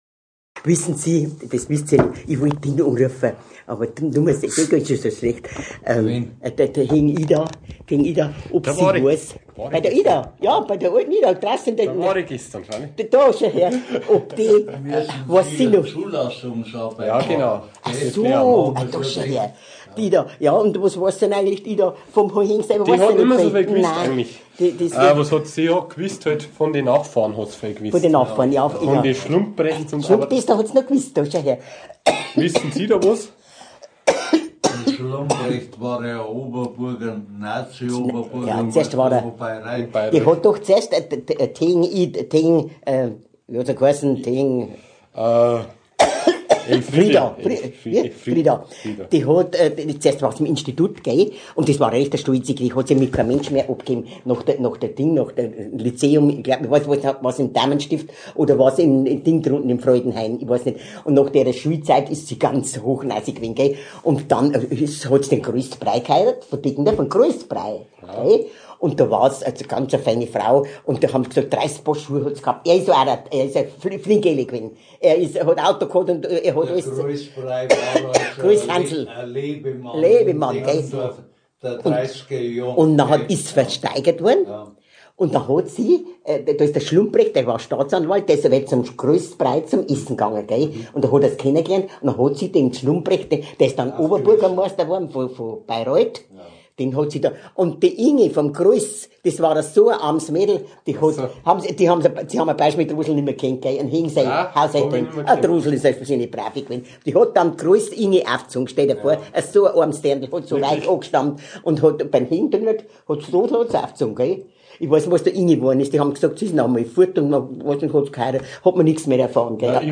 interview.m4a